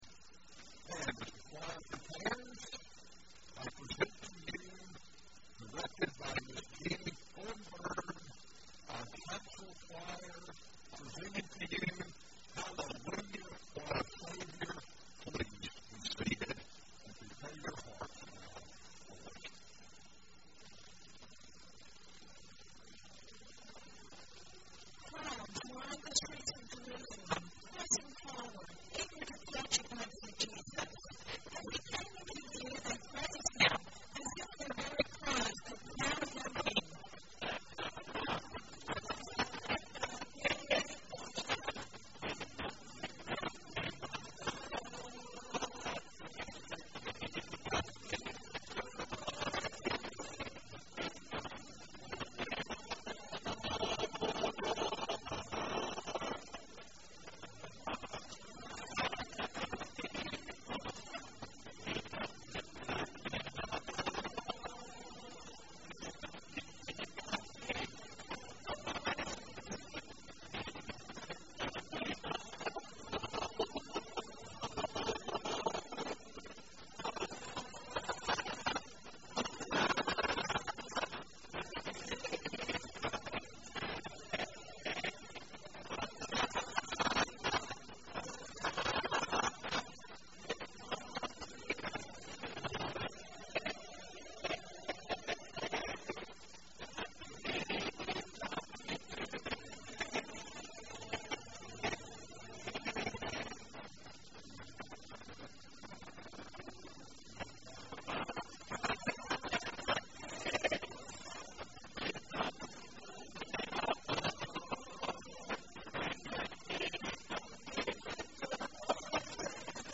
Easter Cantata - April 21, 2019 - McCormick Methodist Church
Cantata